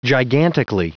Prononciation du mot gigantically en anglais (fichier audio)
Prononciation du mot : gigantically